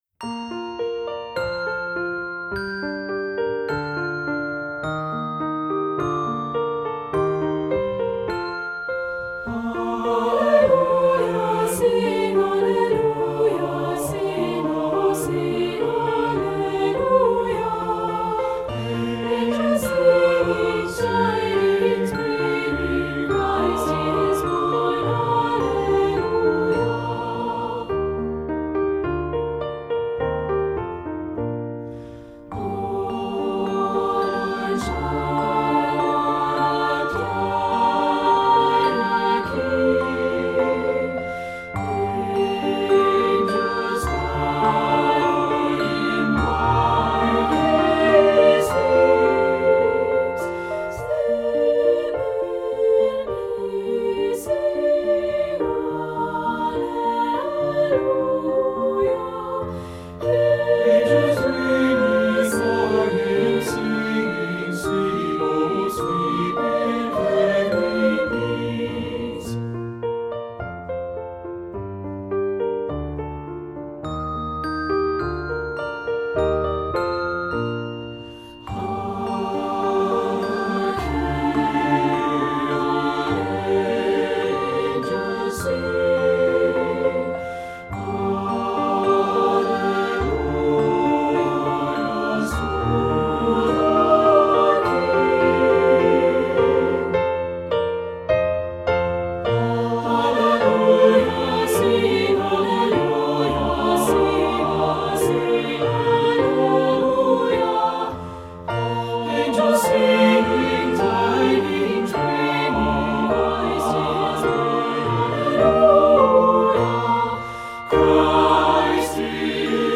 Voicing: SA(T)B